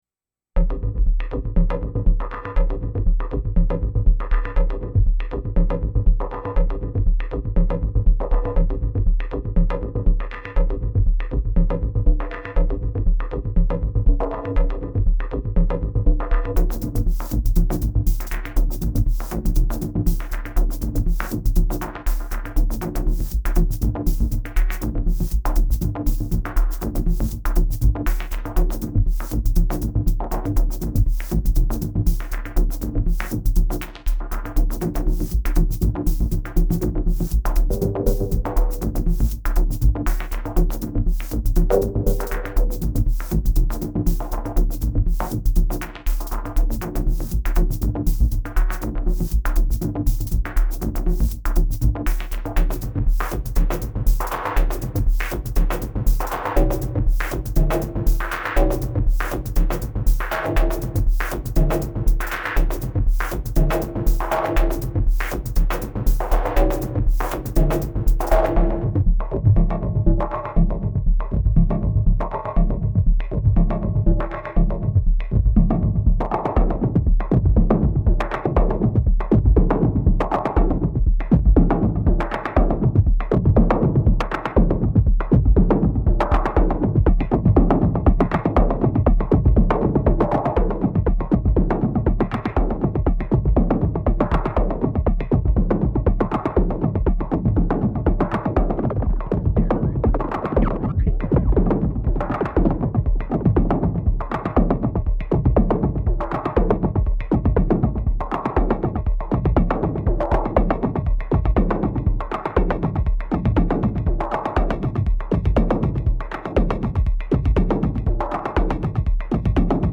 I wanted to do some FM drums/percussion but it wasn’t quite interesting enough.
Still not a great arrangement or anything but I dig the sounds. 8 tracks of BD Modern.
The combo of the sine arpeggios and the toms are nice. Quite a soothing atmosphere despite being quite busy and “propulsive”.